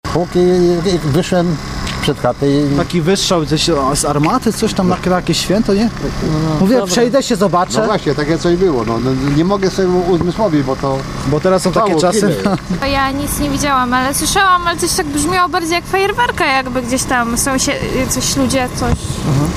- mówili świadkowie.